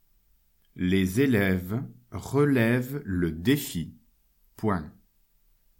Vous retrouvez ici les phrases à écrire, lues par le maître.